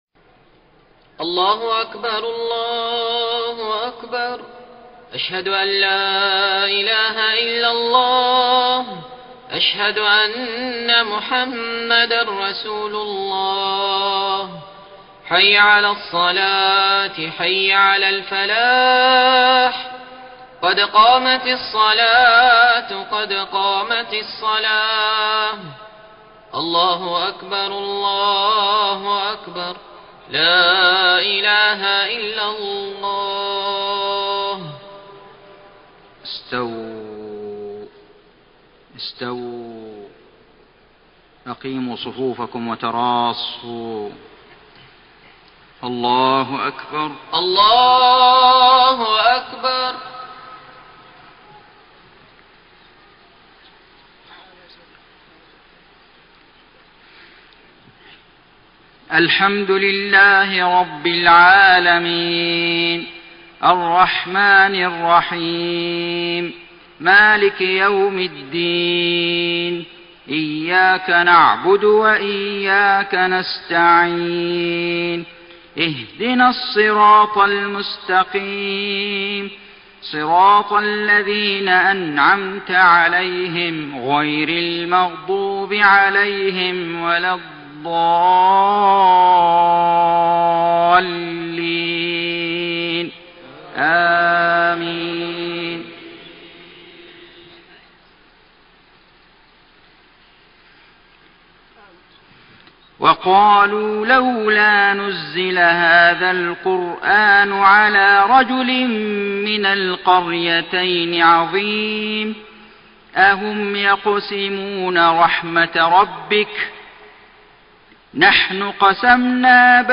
صلاة العشاء 16 رجب 1433هـ من سورة الزخرف 31-45 > 1433 🕋 > الفروض - تلاوات الحرمين